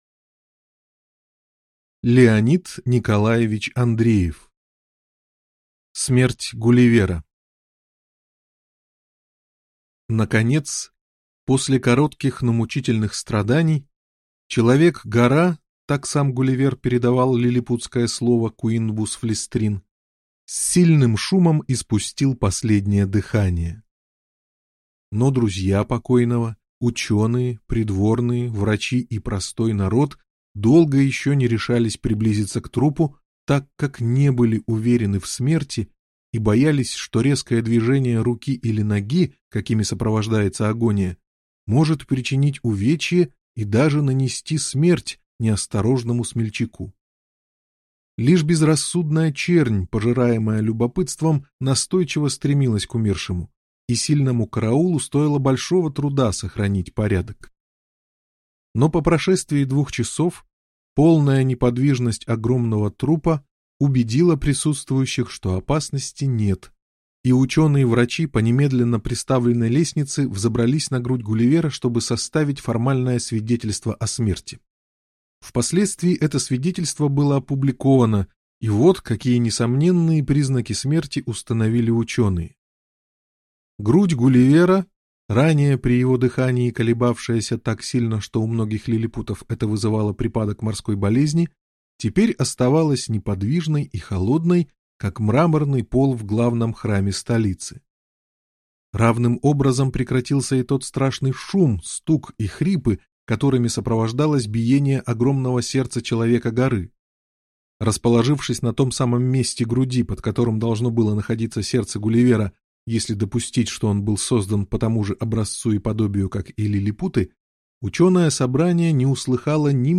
Аудиокнига Смерть Гулливера | Библиотека аудиокниг